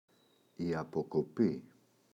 αποκοπή, η [apoko’pi]